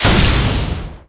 swaeryeong_explo.wav